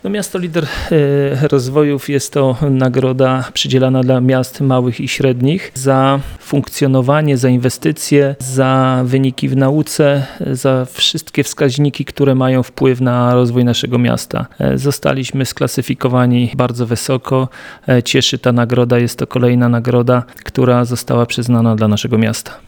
Mówi prezydent Mielca, Jacek Wiśniewski.